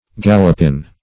Search Result for " gallopin" : The Collaborative International Dictionary of English v.0.48: Gallopin \Gal"lo*pin\, n.[F. galopin.